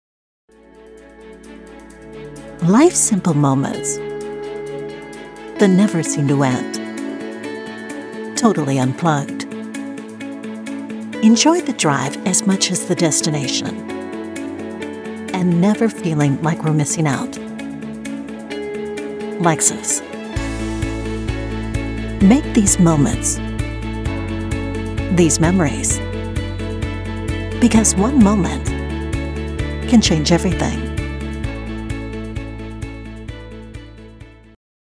Commercial, Luxury, Automotive
Middle Aged
My voice is unique, with a velvety warm and raspy quality. A sound that embodies confidence with tones that are conversational and inviting.
A professional studio equipped to record and edit your audio projects with professional broadcast audio quality.
Lexus wMusic_commercial.mp3